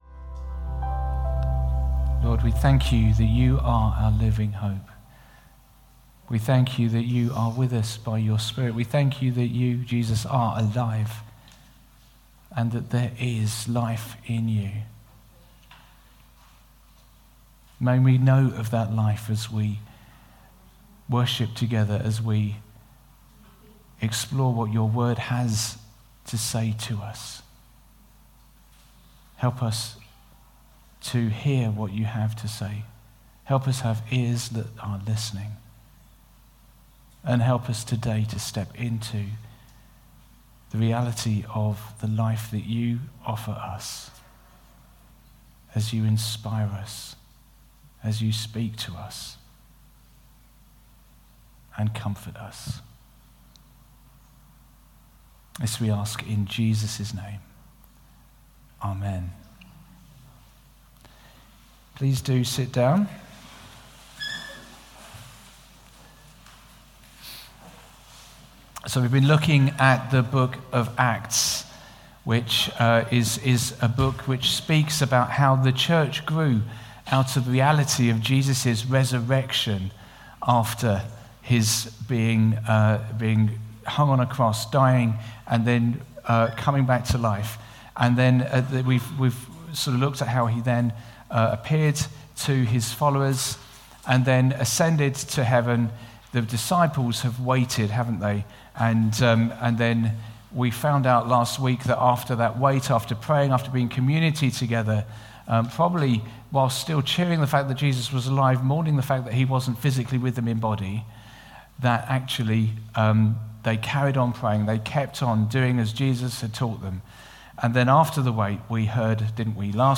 Sermon 16th June 2024 11am gathering
We have recorded our talk in case you missed it or want to listen again.